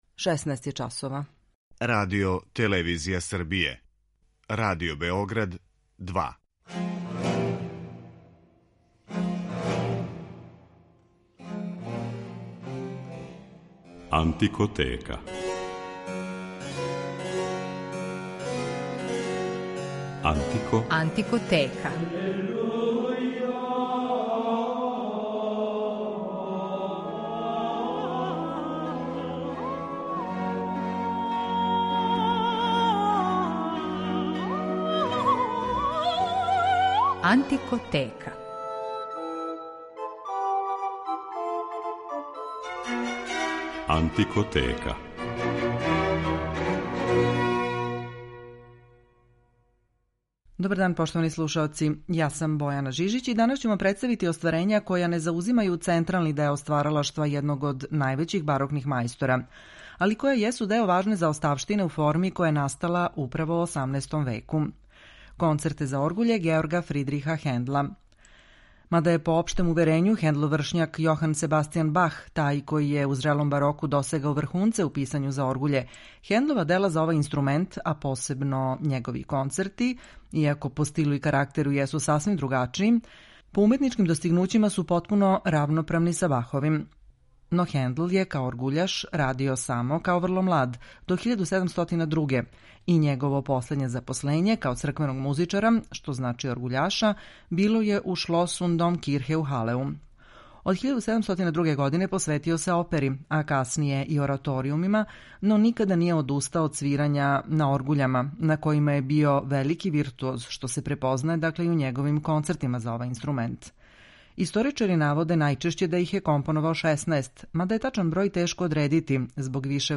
Мале оргуље из 17. века
Њима је посвећена данашња емисија у којој ћете, у рубрици „Антикоскоп", моћи да чујете како су настале и како звуче мале оргуље из 17. века градитеља Георга Рајхела у Цркви Свете Марије у Хендловом родном граду Халеу, на којима је композитор учио да свира и на којима је као млад често музицирао.